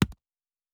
UI Tight 03.wav